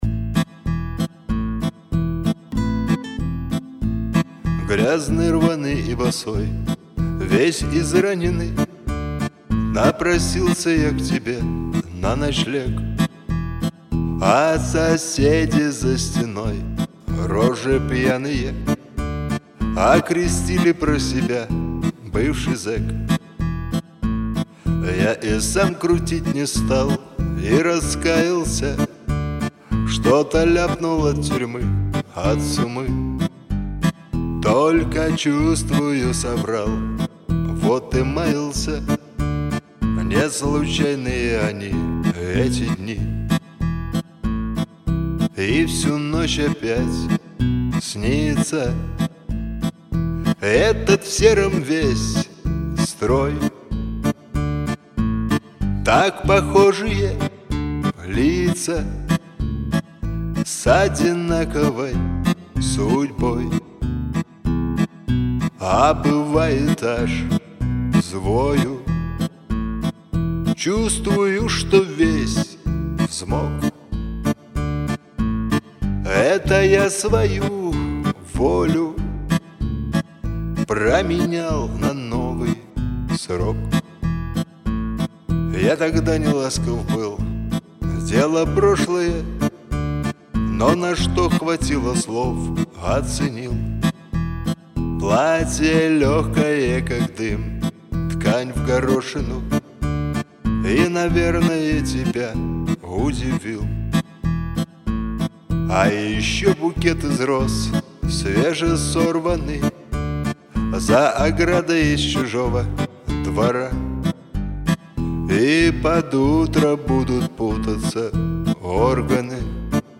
акустическая версия